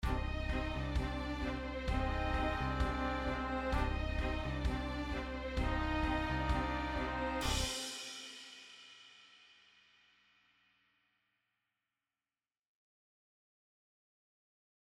Short version 1
Plays long end of track